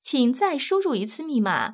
ivr-please_reenter_your_pin.wav